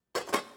Babushka / audio / sfx / Kitchen / SFX_Cooking_Pot_01_Reverb.wav
SFX_Cooking_Pot_01_Reverb.wav